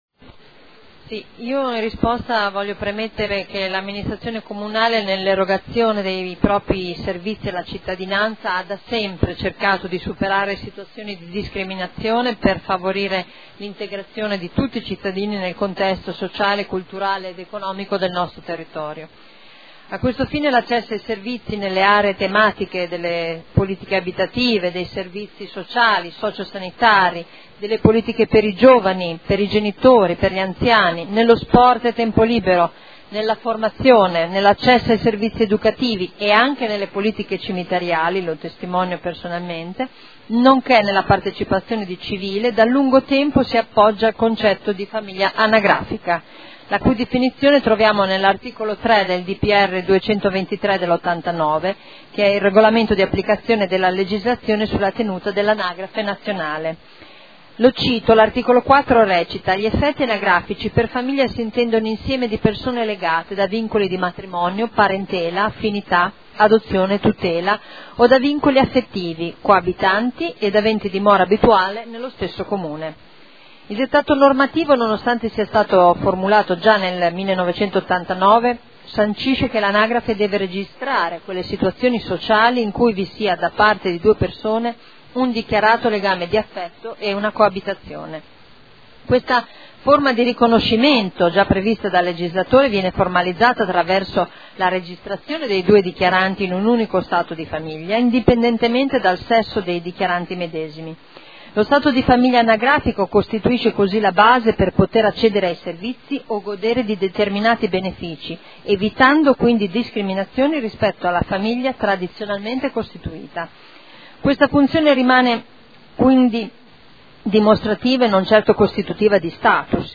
Simona Arletti — Sito Audio Consiglio Comunale
Seduta del 3 aprile. Interrogazione del consigliere Ricci (SEL) avente per oggetto: “Unioni civili”.